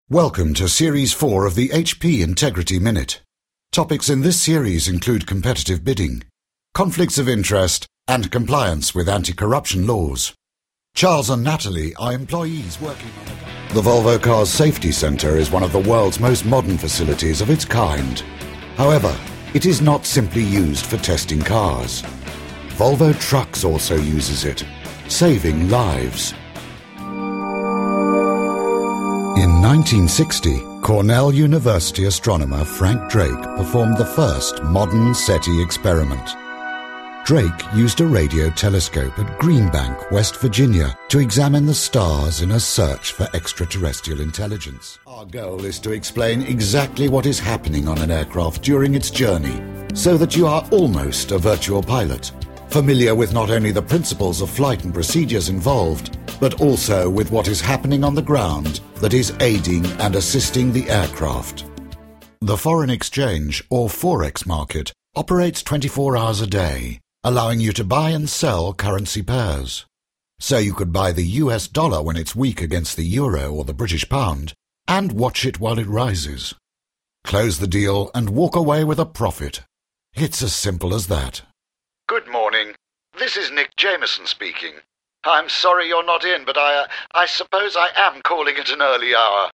Englisch (UK)
Männlich